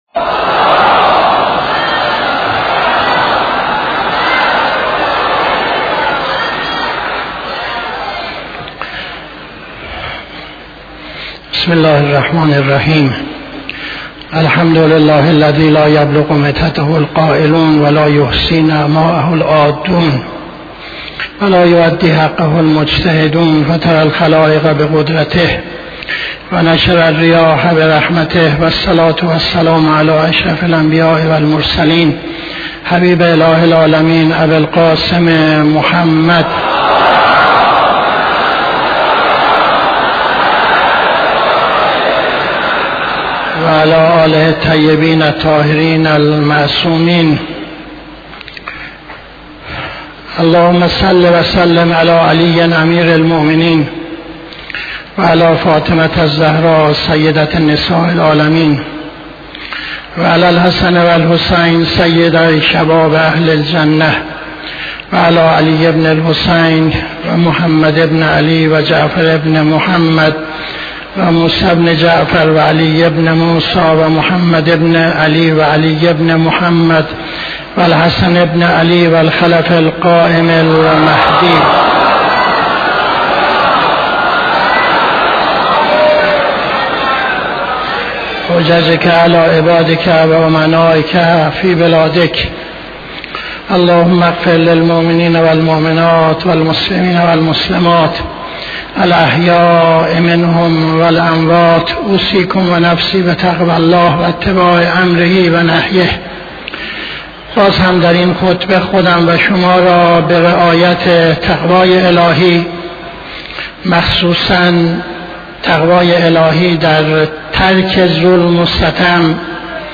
خطبه دوم نماز جمعه 28-08-78